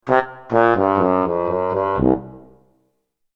Comical Trumpet Fail Sound Effect
Description: Comical trumpet fail sound effect. A silly trumpet melody that signals a mistake or wrong answer.
Comical-trumpet-fail-sound-effect.mp3